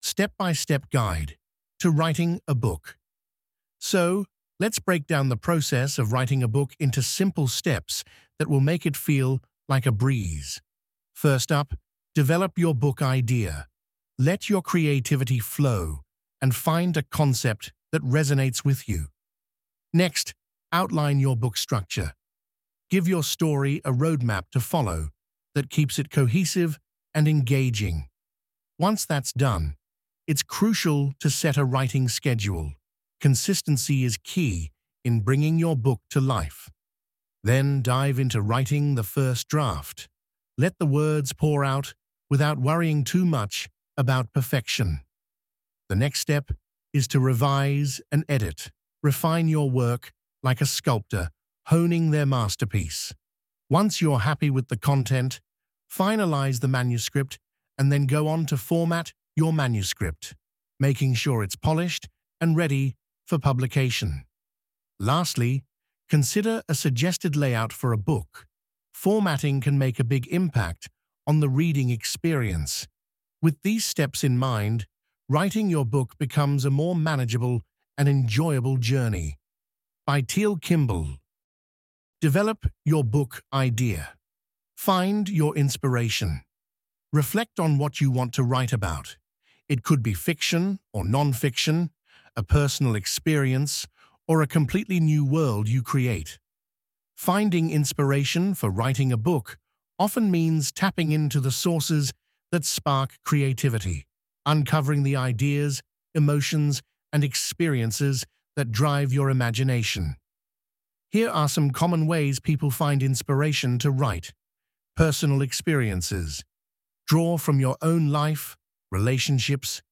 Full Audio Lecture MP3